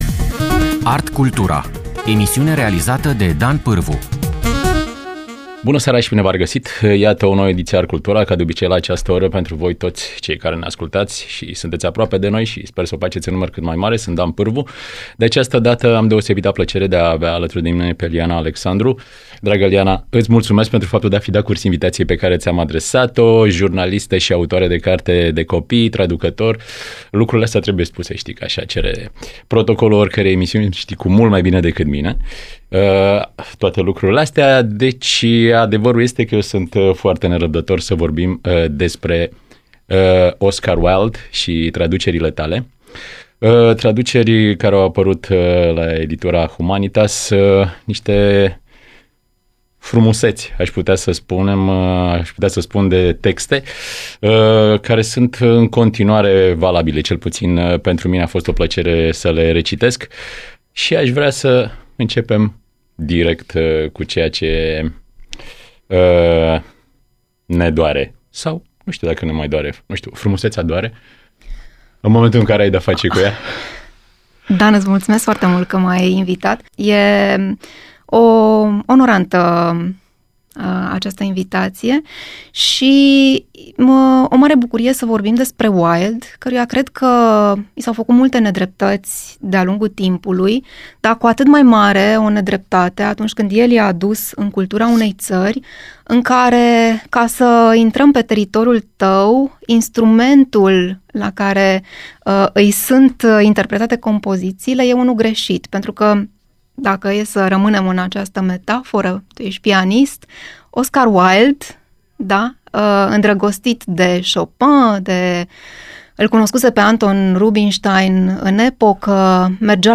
Emisiune – interviu